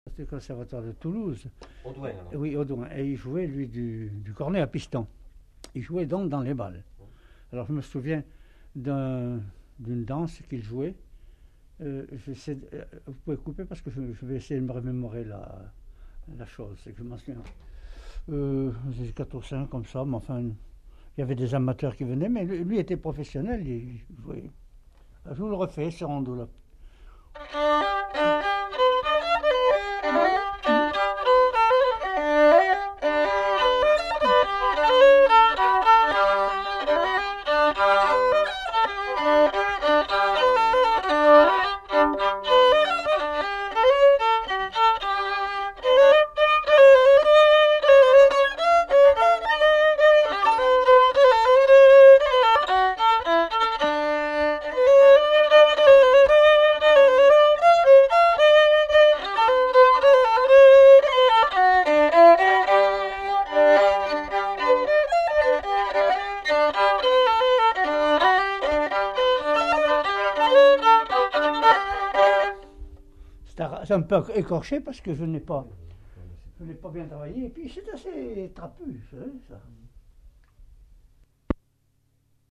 Aire culturelle : Haut-Agenais
Lieu : Castillonnès
Genre : morceau instrumental
Instrument de musique : violon
Danse : rondeau